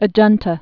(ə-jŭntə)